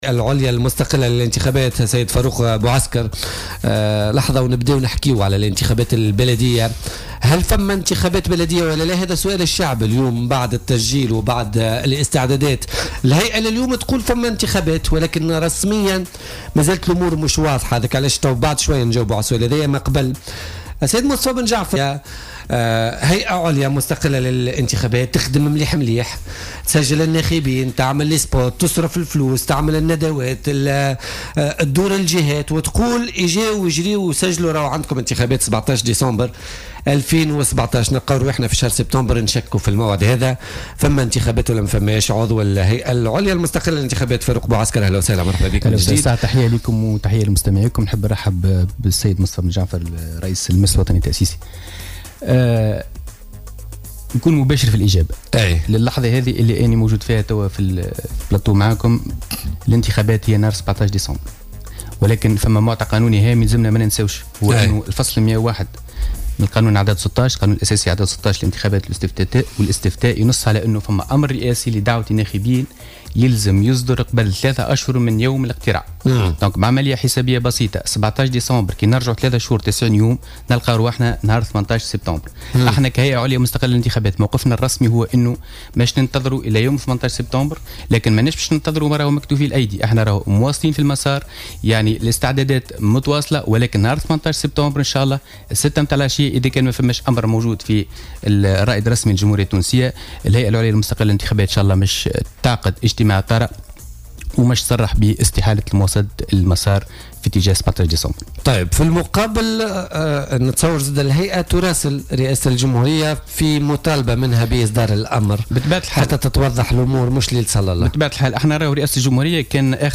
وأكد ضيف "بوليتيكا" في "الجوهرة أف أم" أهمية صدور الأمر الرئاسي في آجاله القانونية، مشددا بالقول إن هيئة الانتخابات تواصل استعداداتها لهذه الانتخابات بشكل طبيعي رغم حالة الارباك التي تعيشها.